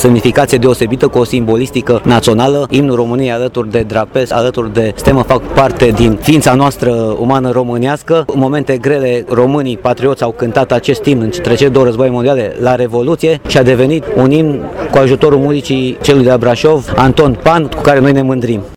Instituția Prefectului Județului Brașov în parteneriat cu Garnizoana Braşov a organizat, în Piaţa Tricolorului, o manifestare specială, dedicată sărbătoririi Zilei Imnului Naţional al României.
Subprefectul Braşovului, Ciprian Băncilă: